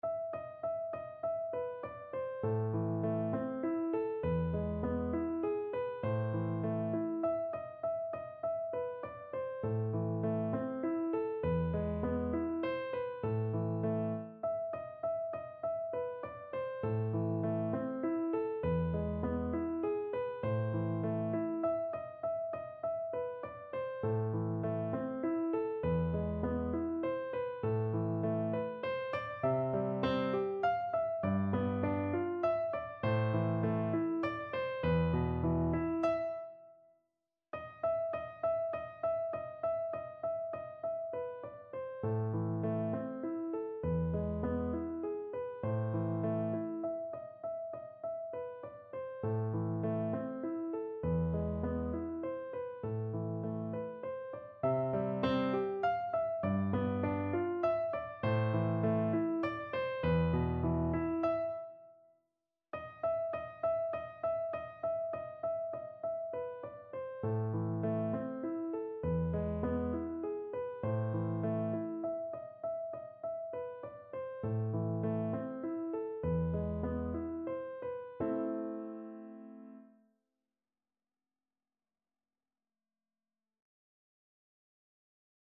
Free Sheet music for Piano Four Hands (Piano Duet)
Bagatelle in A minor.
A minor (Sounding Pitch) (View more A minor Music for Piano Duet )
=150 Moderato
Classical (View more Classical Piano Duet Music)